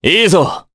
Crow-Vox_Happy4_jp.wav